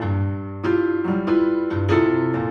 Index of /musicradar/gangster-sting-samples/95bpm Loops
GS_Piano_95-G1.wav